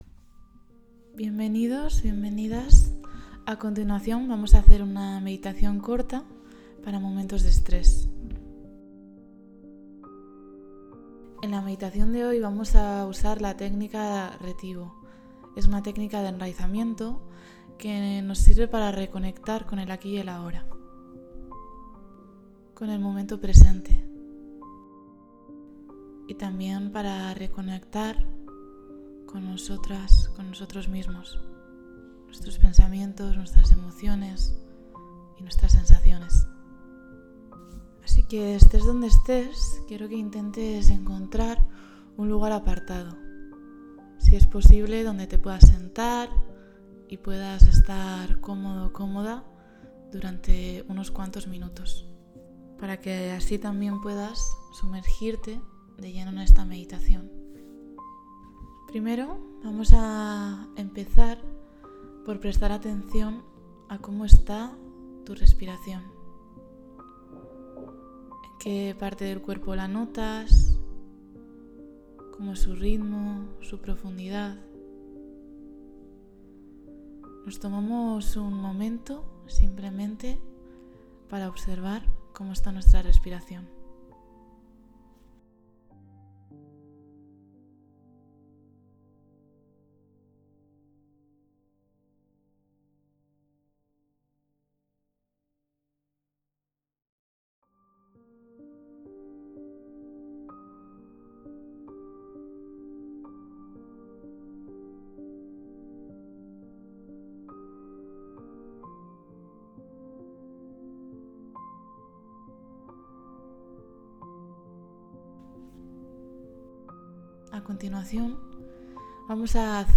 Meditacion-para-momentos-de-estres.mp3